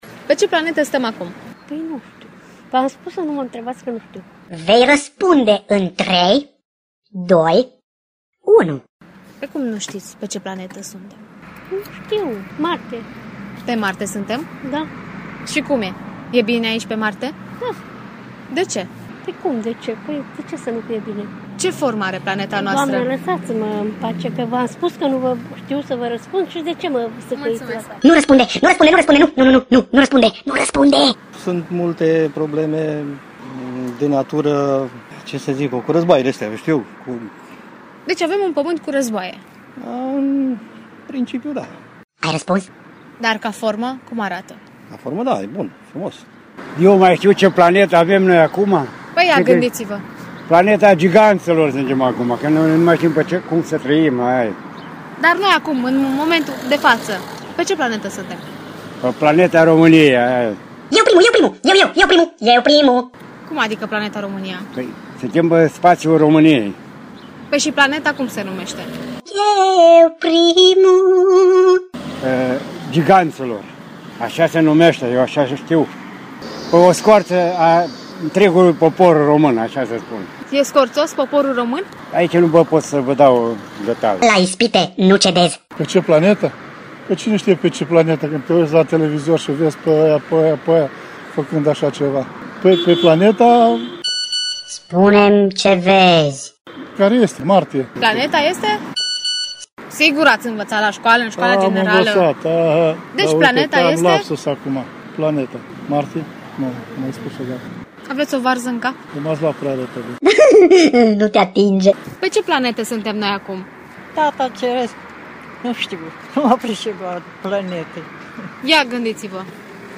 Am provocat câţiva reşiţeni să ne răspundă la această întrebare. Pe cât de banală este întrebarea pe atât de haioase au fost răspunsurile….Află mai multe aici!